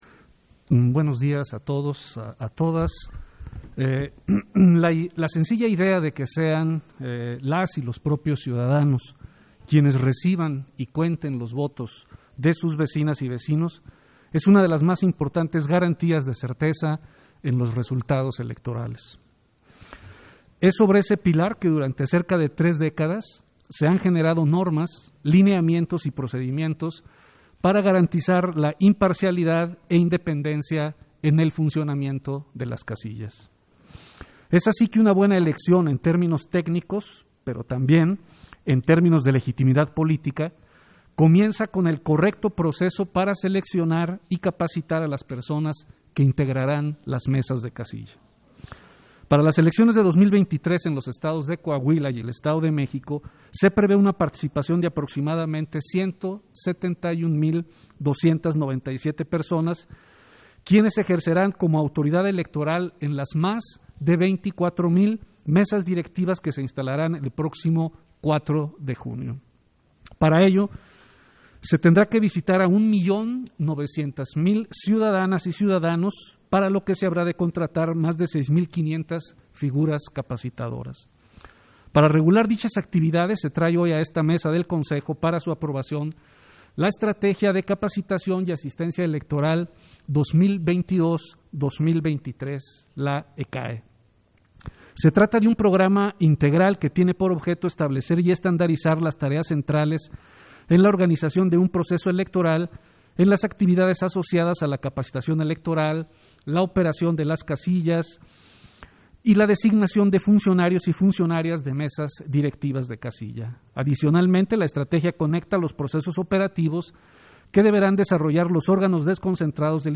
Intervención de Martín Faz, en el punto 1 de la Sesión Extraordinaria, por el que se aprueba la estrategia de capacitación y asistencia electoral 2022-2023 y sus anexos